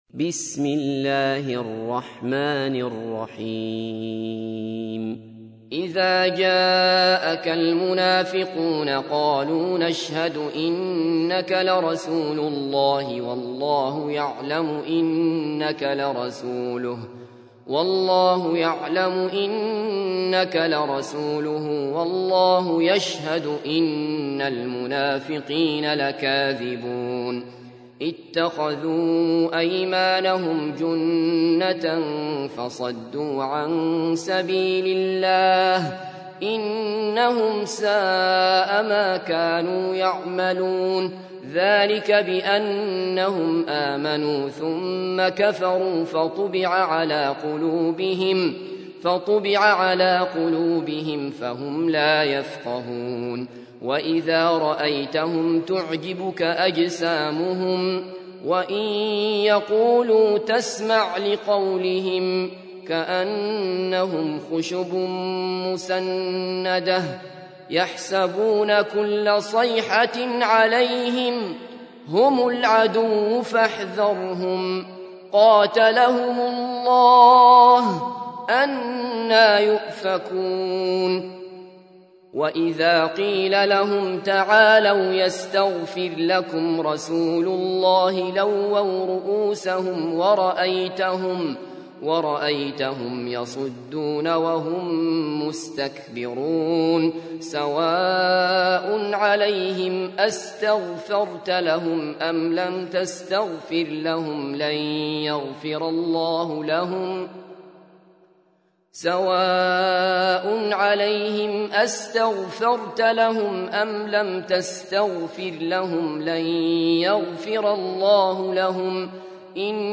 تحميل : 63. سورة المنافقون / القارئ عبد الله بصفر / القرآن الكريم / موقع يا حسين